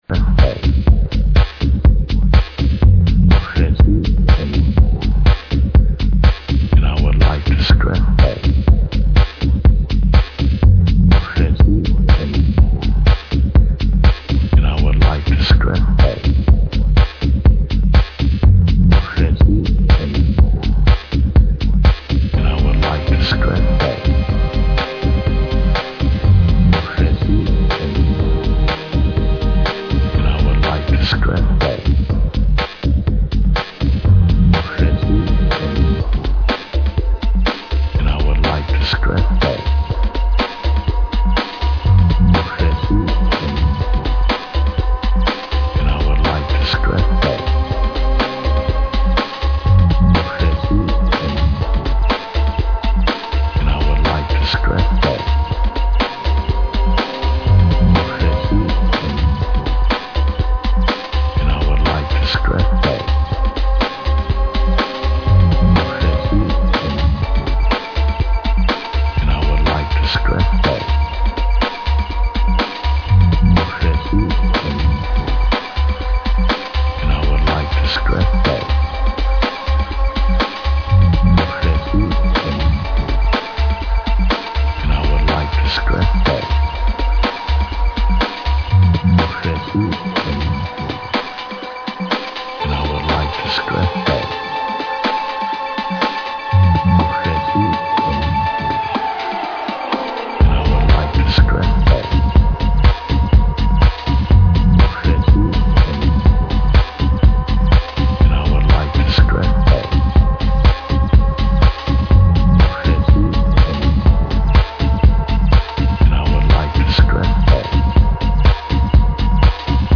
minimal/tech-ish smashing original